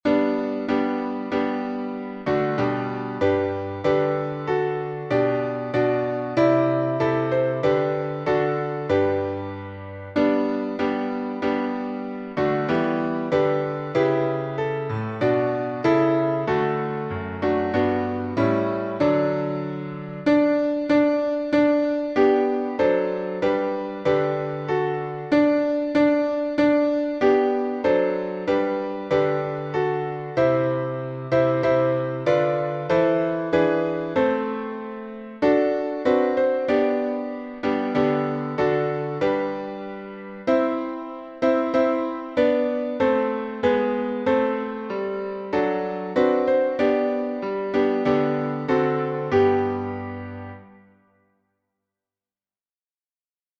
#1042: Hark the Herald Angels Sing — 4 stanzas in G | Mobile Hymns